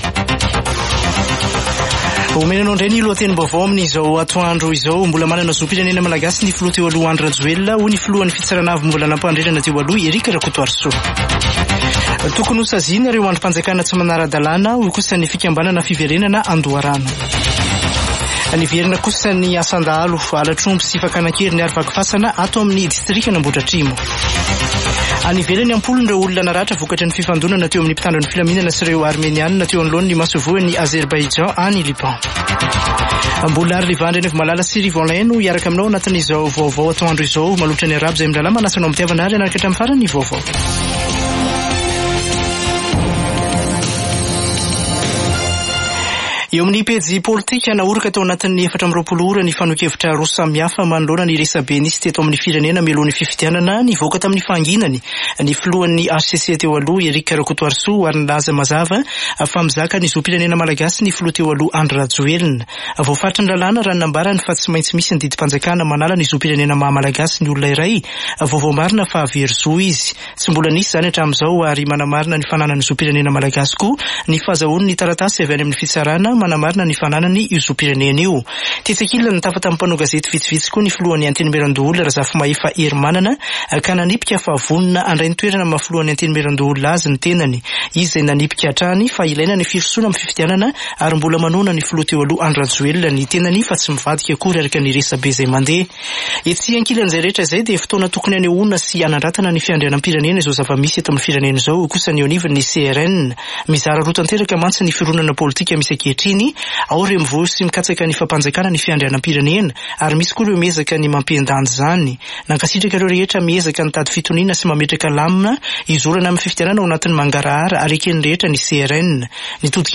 [Vaovao antoandro] Zoma 29 septambra 2023